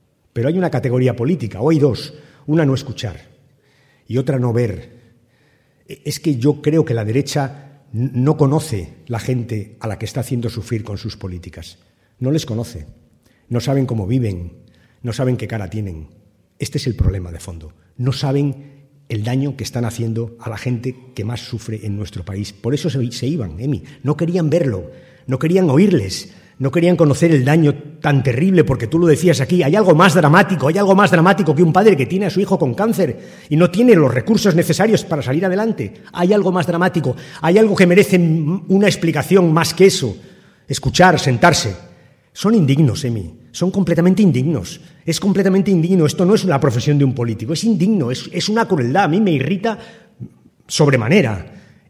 En el Teatro Victoria, de Talavera de la Reina, donde 600 militantes y simpatizantes socialistas han acompañado a Rubalcaba, y donde han intervenido además el líder de los socialistas castellano-manchegos, Emiliano García Page, y el candidato al Parlamento Europeo Sergio Gutiérrez, el Secretario General del PSOE ha recordado además que Cospedal se estrenó como presidenta de Castilla-La Mancha quitando las ayudas a las mujeres víctimas de la violencia de género, “precisamente las mujeres que más ayuda necesitan”.